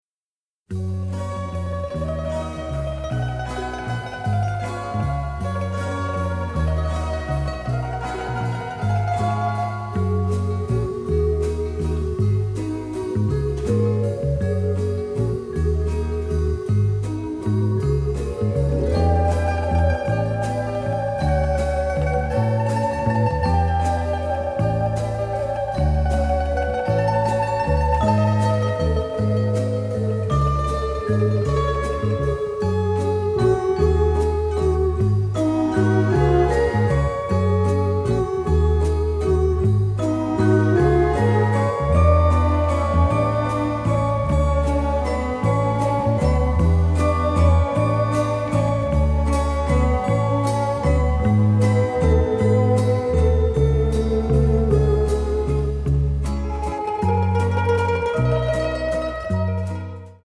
soundtrack album
builds upon classic Italian songs
original stereo session elements